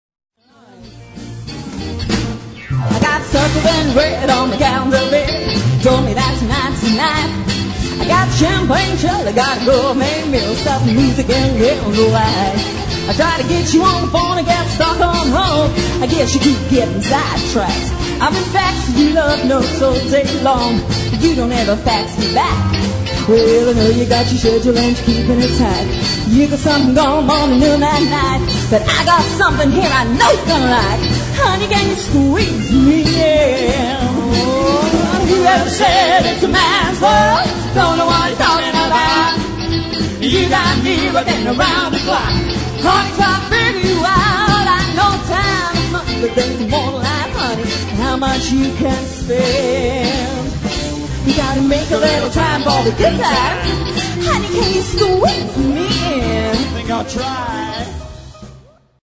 in a duet